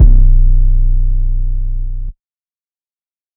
808 (Hurricane).wav